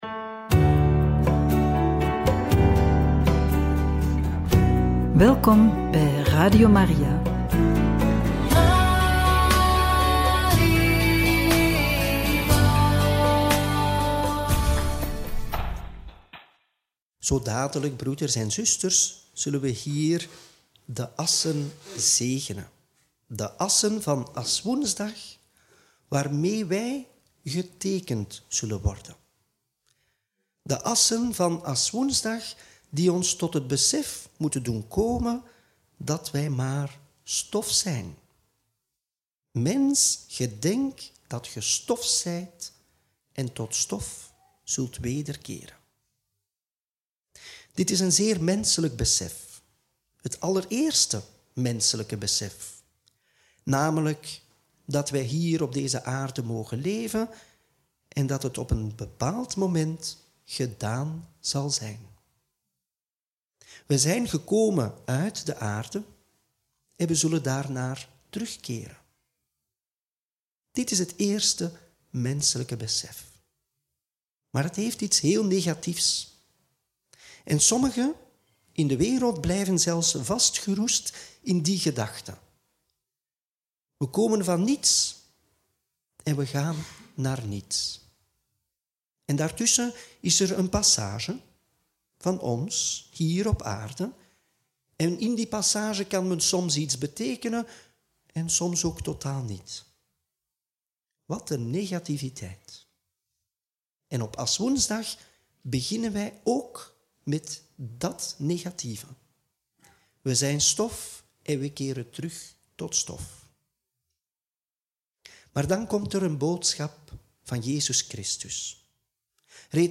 Homilie bij het Evangelie van woensdag 5 maart 2025 – Aswoensdag – Mt 6, 1-6; 16-18